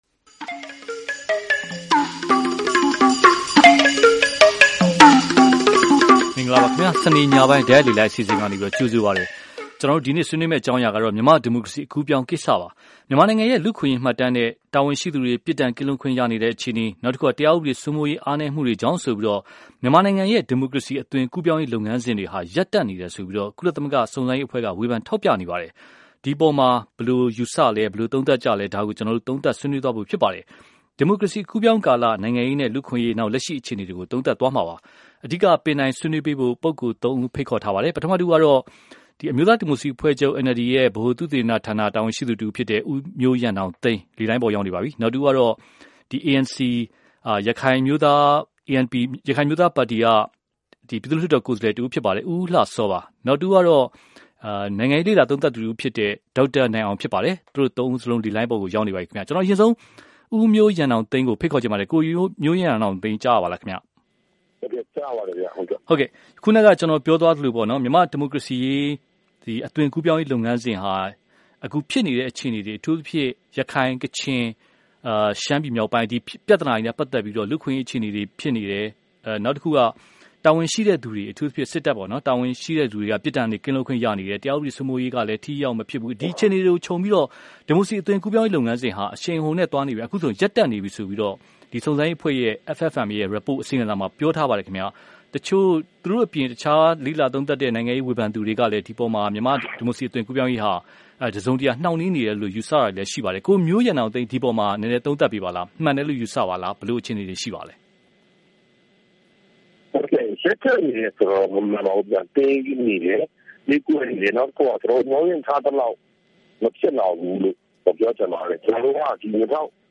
မြန်မာ့ဒီမိုကရေစီအသွင်ကူးပြောင်းမှု ရပ်တန့်နေသလား (တိုက်ရိုက်လေလှိုင်း)